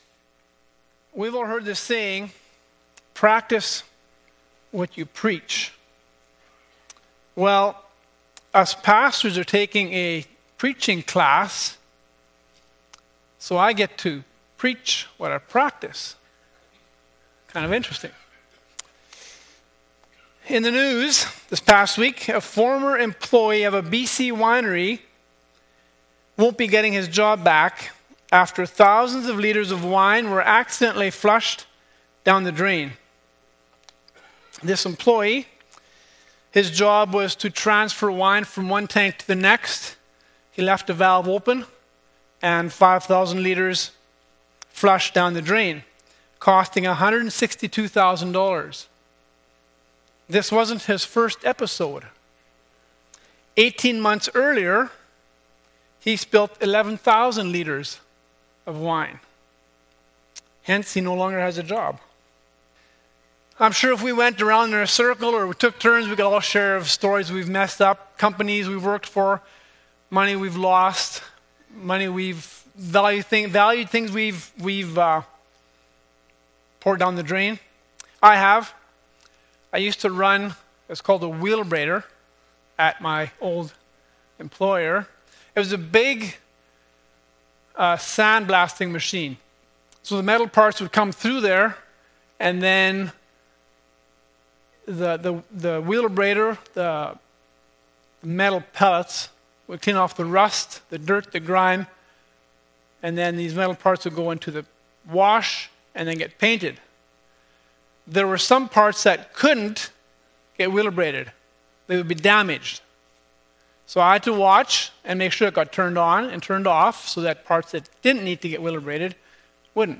2:2 Service Type: Sunday Morning Bible Text